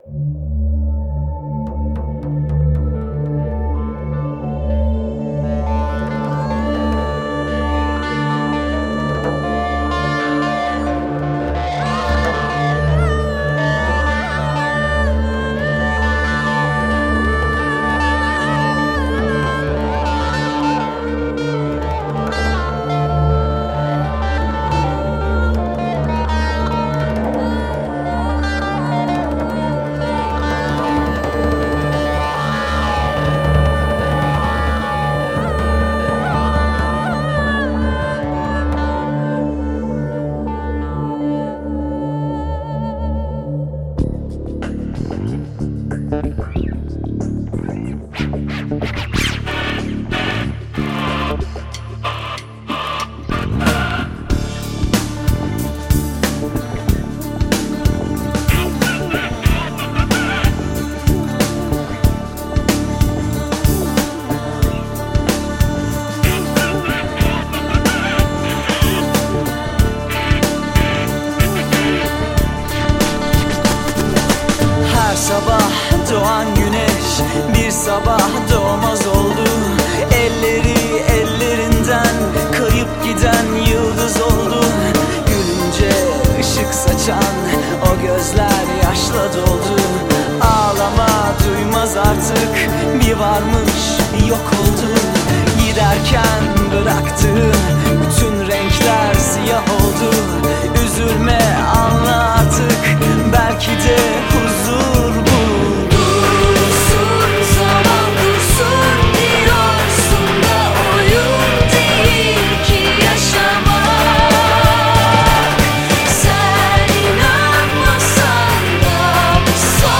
دانلود آهنگ راک ترکی Rock turkish music